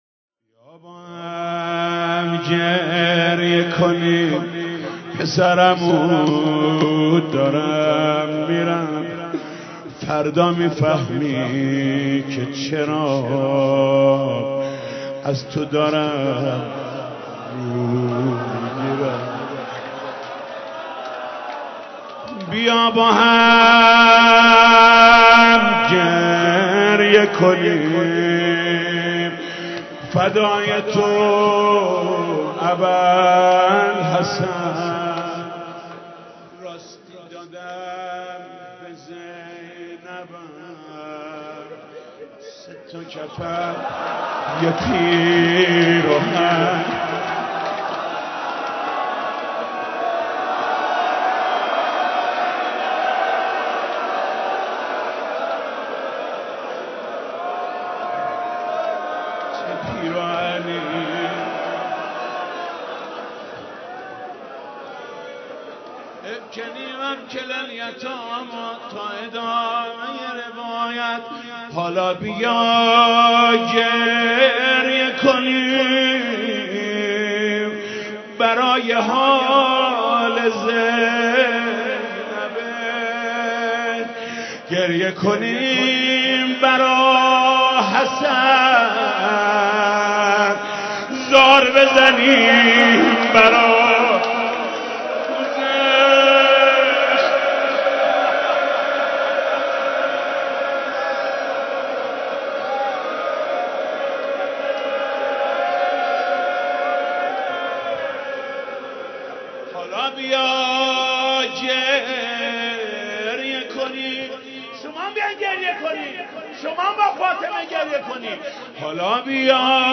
بیا با هم گریه کنیم (روضه)